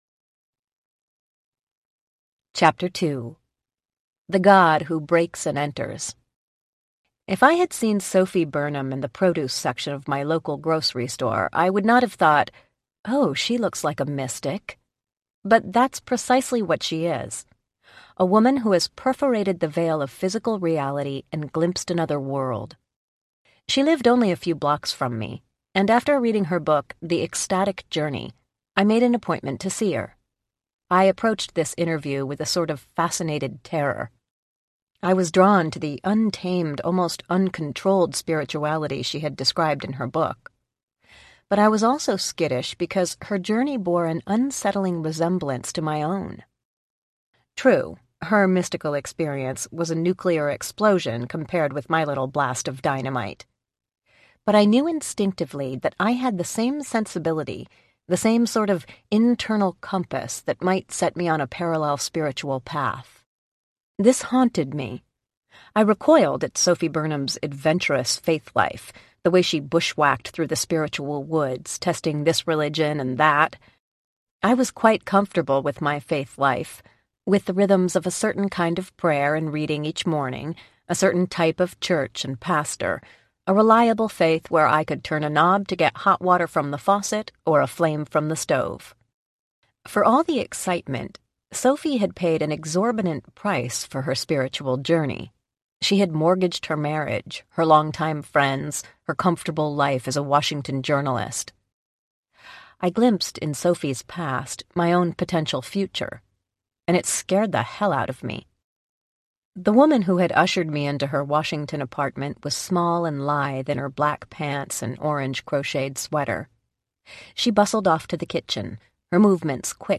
Fingerprints of God Audiobook
Narrator